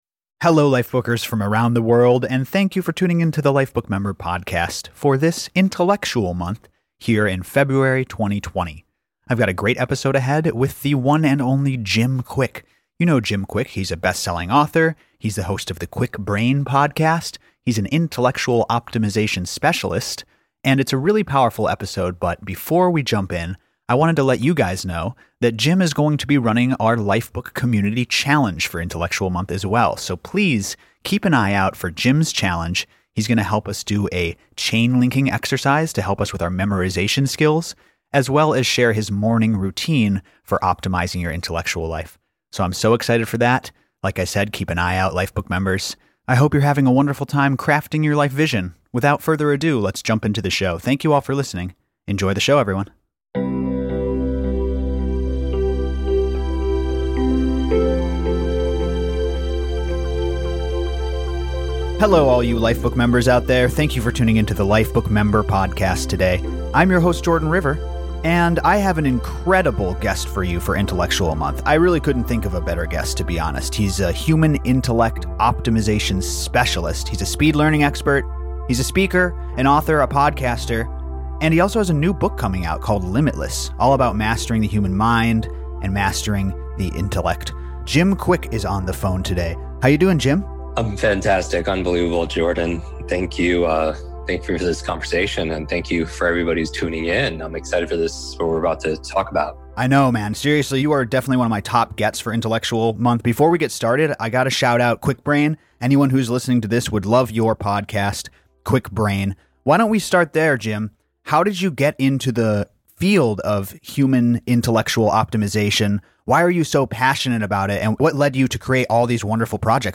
Expert Interview: Kwik Intellectual Insights with Jim Kwik